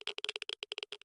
Minecraft Version Minecraft Version latest Latest Release | Latest Snapshot latest / assets / minecraft / sounds / ambient / nether / basalt_deltas / click2.ogg Compare With Compare With Latest Release | Latest Snapshot
click2.ogg